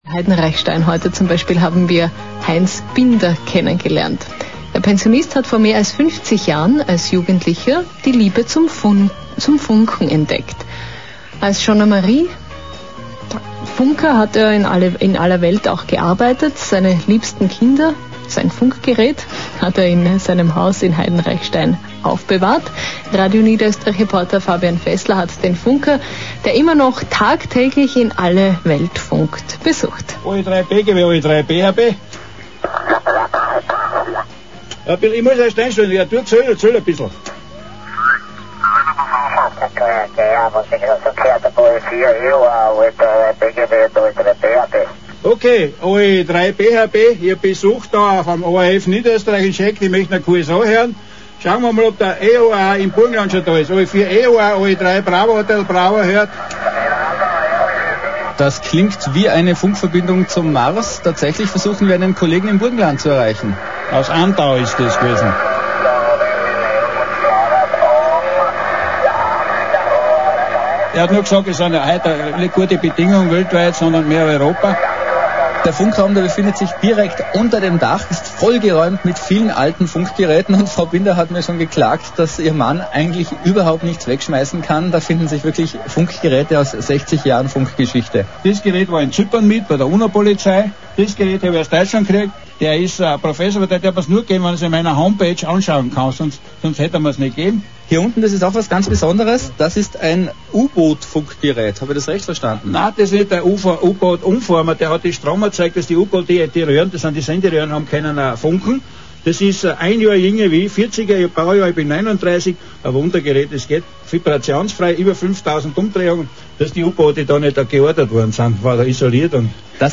hier ein Auszug dieser Sendung, welche in Radio N.Ö. zu hören war.
Ebenfalls im Radio, aber in Morsezeichen zu vernehmen: guten Morgen N.Ö..